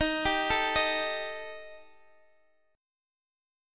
D#Mb5 chord